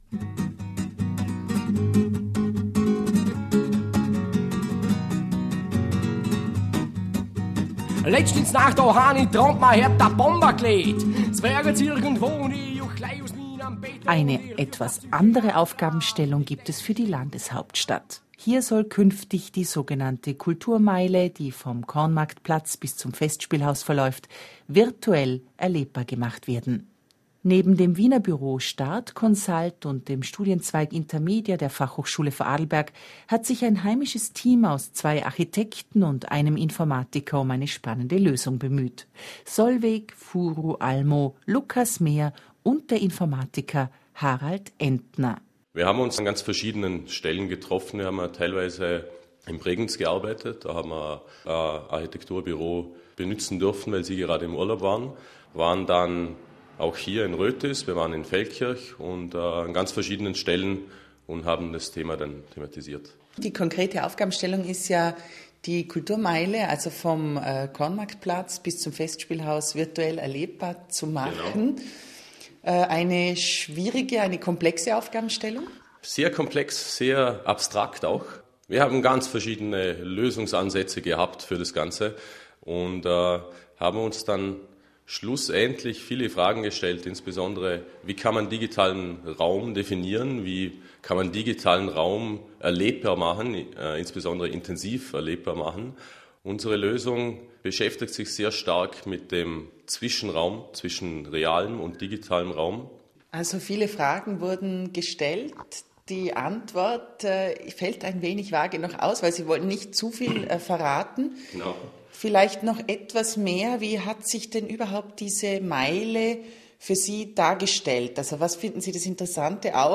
Hierbei handelt es sich um einen Ausschnitt aus der Sendung Kultur nach Sechs , welche am 18.09.2014 in Radio Vorarlberg übertragen wurde.